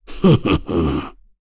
KaselB-Vox-Laugh.wav